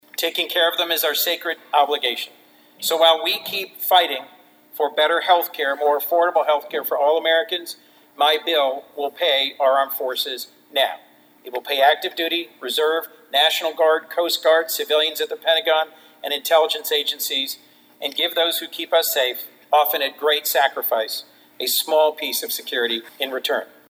Senator Coons spoke on the Senate Floor this afternoon calling for unanimous passage of the bill…